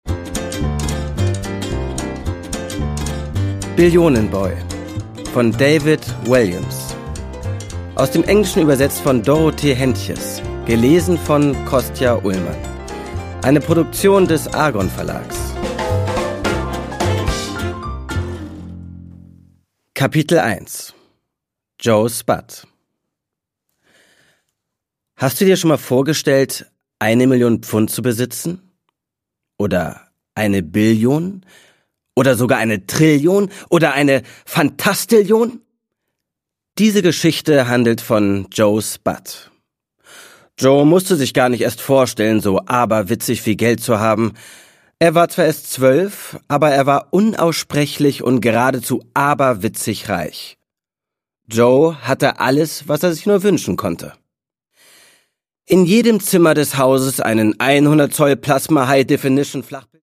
David Walliams: Billionen-Boy (Ungekürzte Lesung)
Produkttyp: Hörbuch-Download
Gelesen von: Kostja Ullmann